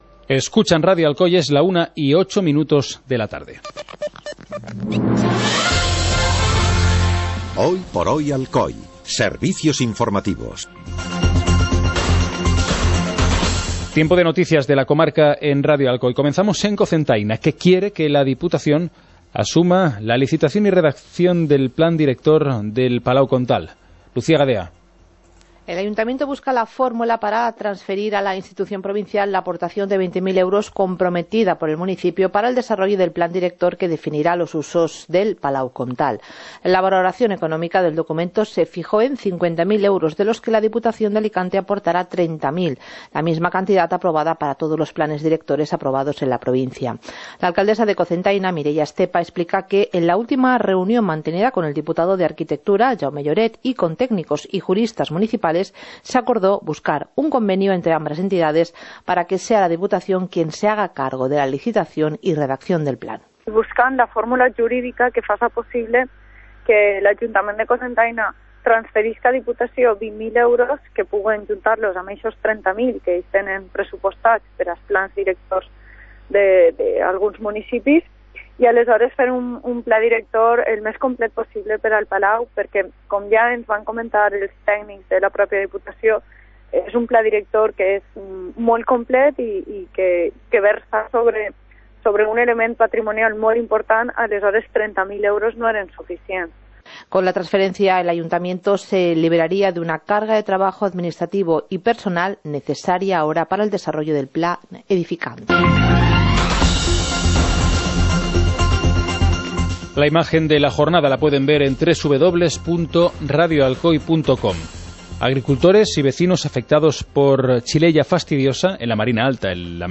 Informativo comarcal - martes, 13 de marzo de 2018